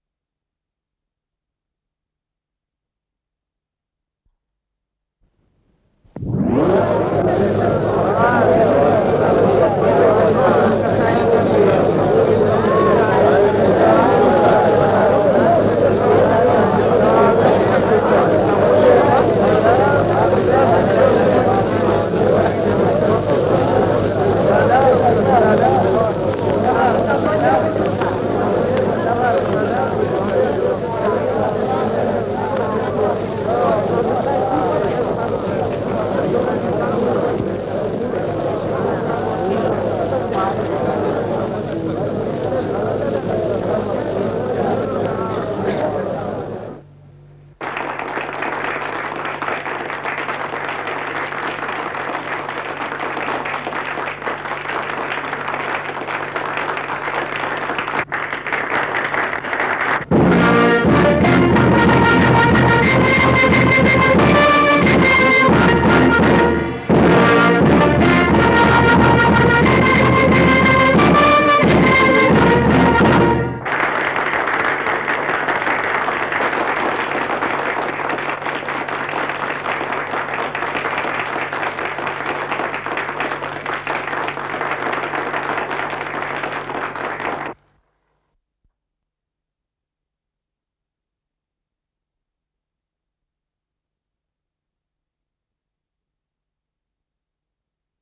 с профессиональной магнитной ленты
Название передачиШум зала, туш, аплодисменты
Скорость ленты38 см/с
Тип лентыШХЗ Тип 6